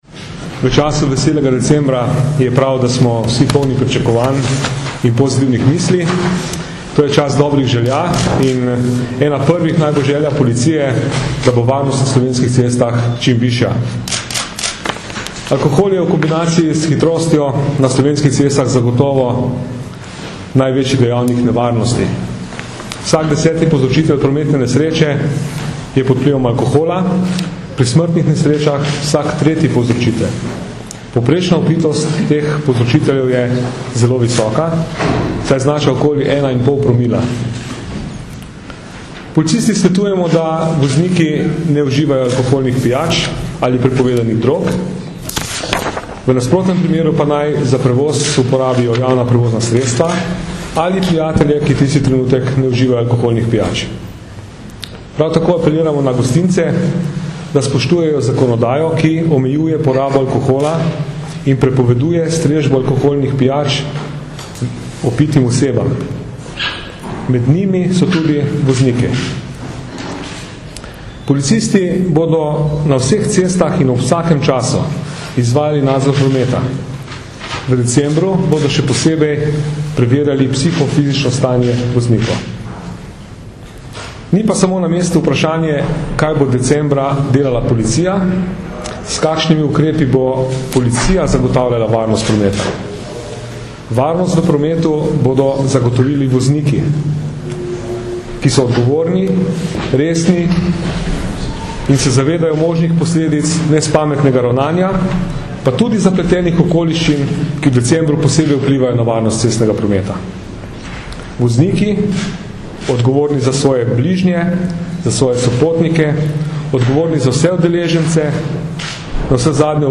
Zvočni posnetek izjave Danijela Žibreta (mp3)